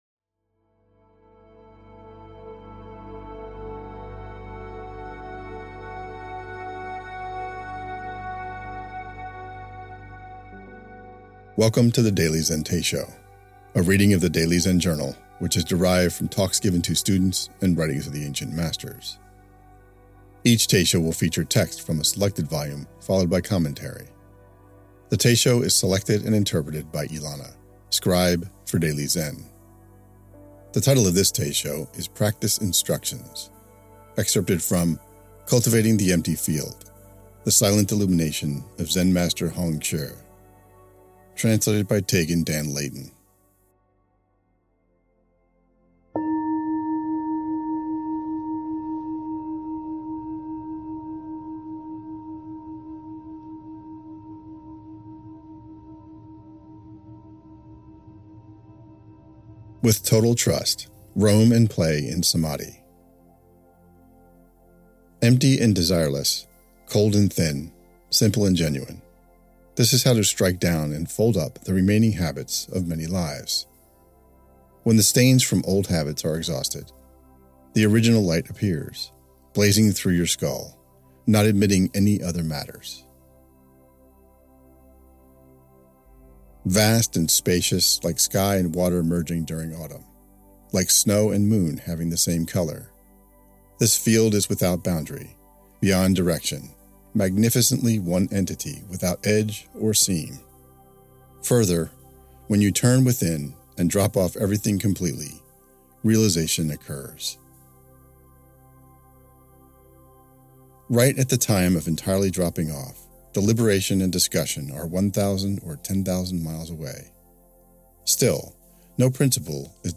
Years ago a reader of Daily Zen asked if we could add a way for those who are visually impaired to hear the readings each month.
practice-instructions.mp3